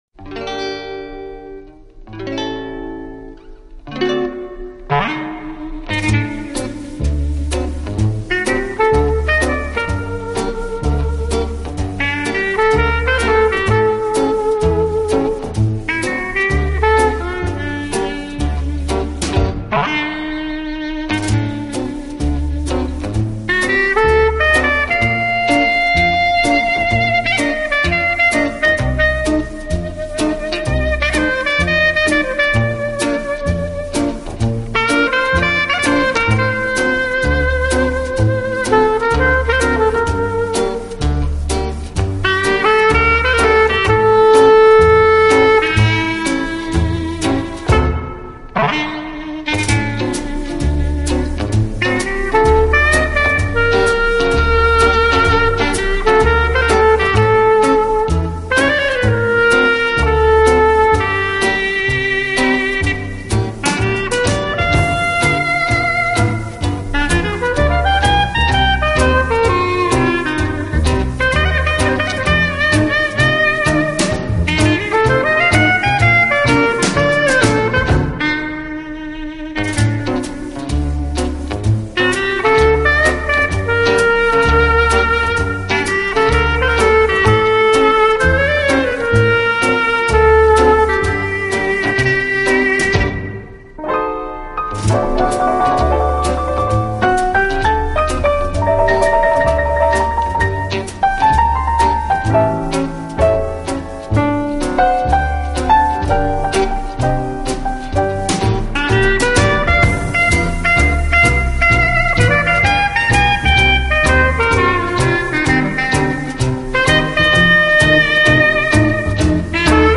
音乐风格：Instrumental/Jazz